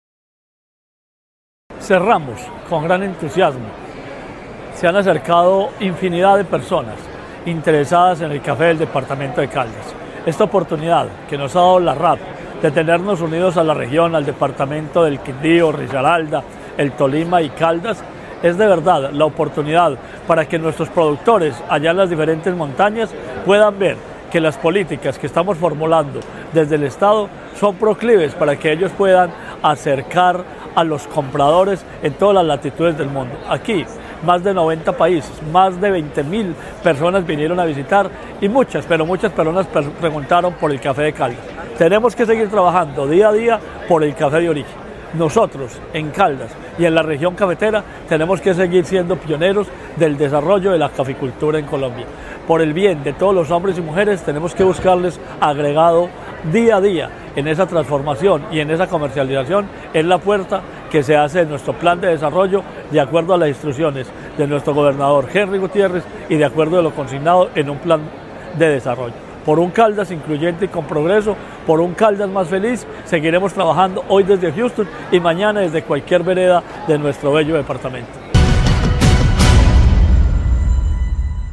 Marino Murillo Franco, secretario de Agricultura y Desarrollo Rural de Caldas